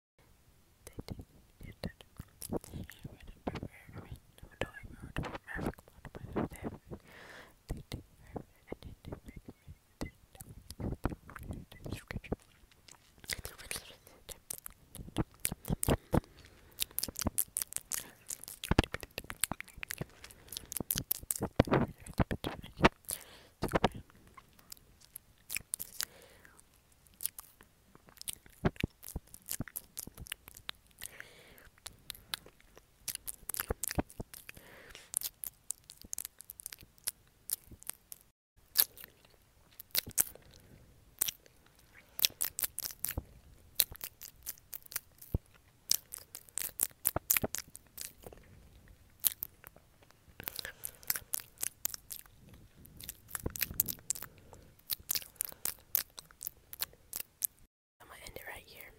Mouth sounds